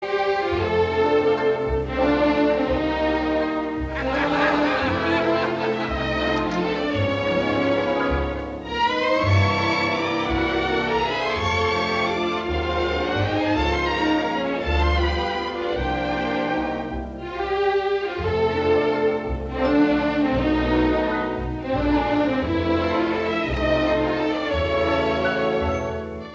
it-should-happen-to-you-1954-trailer-audiotrimmer-com.mp3